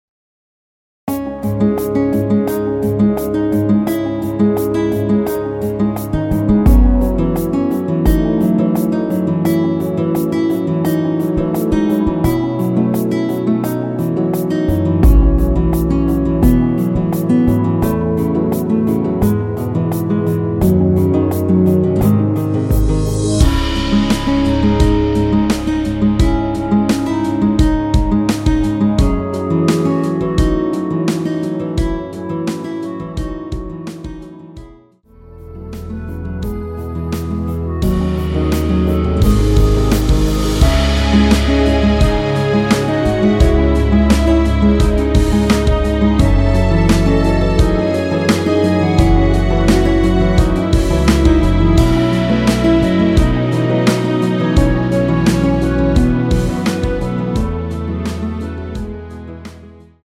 원키에서(-2)내린 멜로디 포함된 MR입니다.
C#m
앞부분30초, 뒷부분30초씩 편집해서 올려 드리고 있습니다.